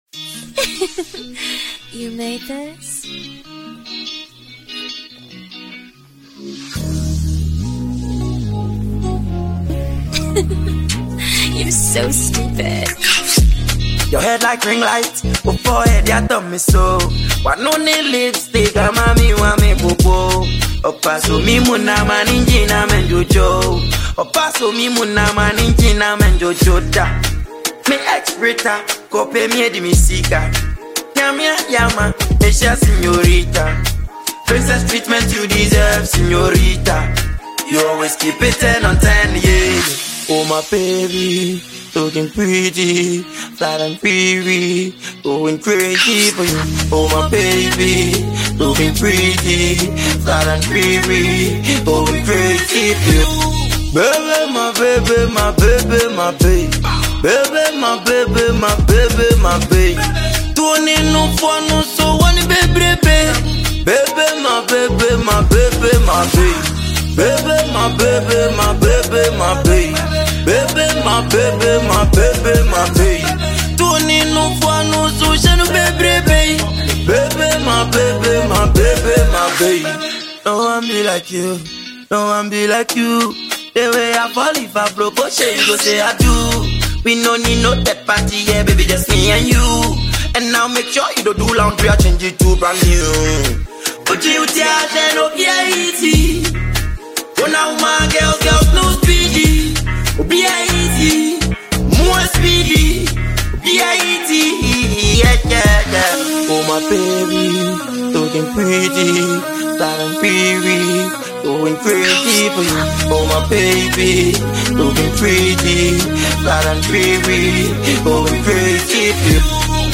Ghanaian rapper and singer